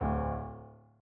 sfx_猪头走路1.ogg